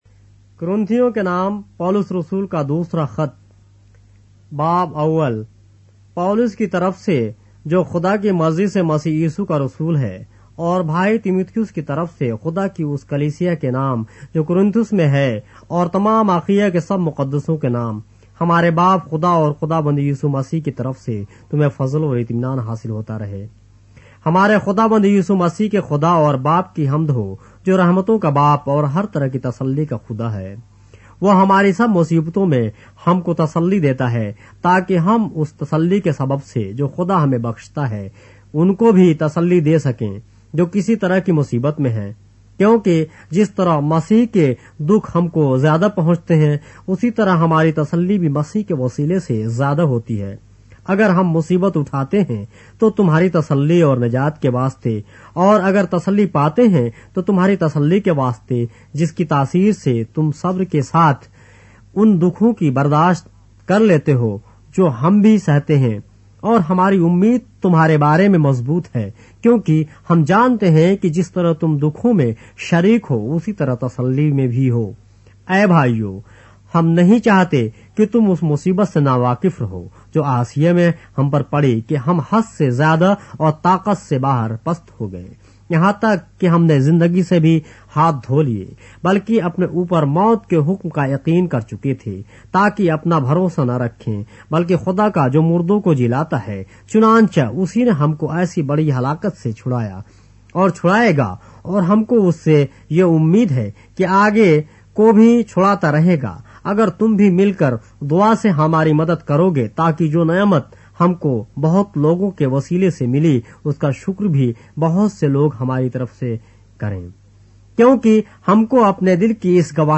اردو بائبل کے باب - آڈیو روایت کے ساتھ - 2 Corinthians, chapter 1 of the Holy Bible in Urdu